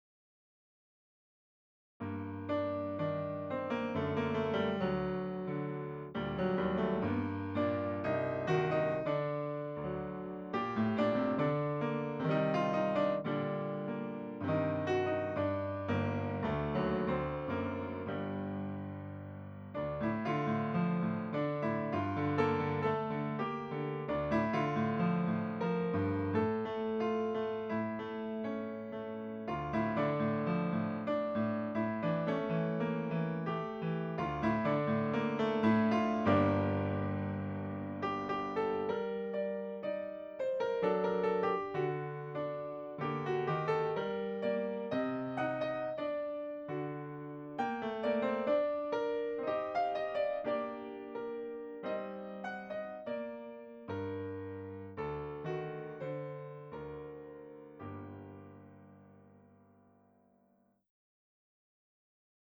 This is one of the only solo piano pieces I have written.